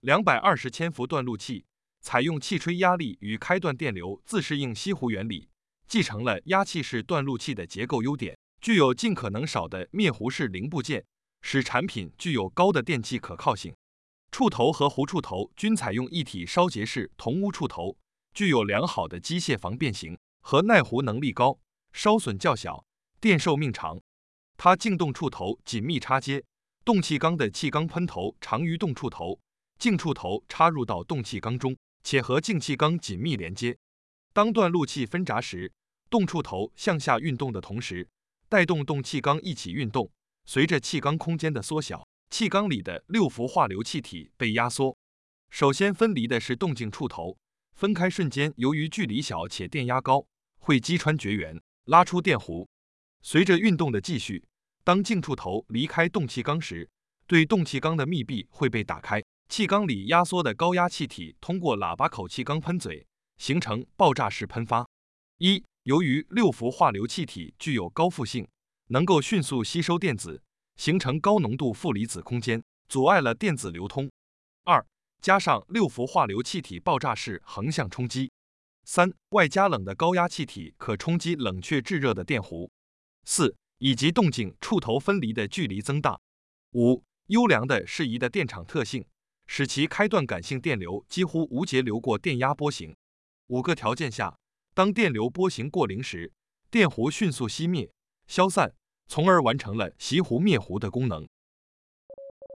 220kV断路器-燃弧熄弧修改后.mp3